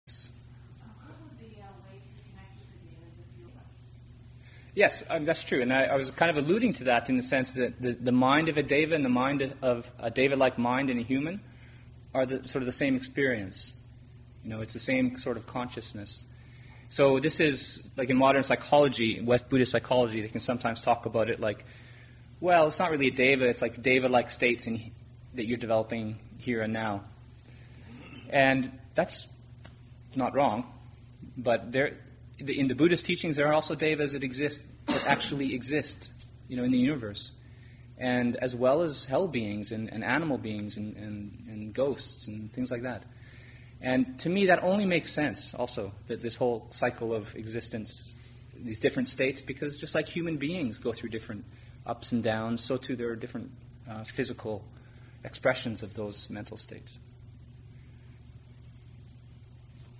Upāsikā Day, Aug. 19, 2012
Abhayagiri Buddhist Monastery in Redwood Valley, California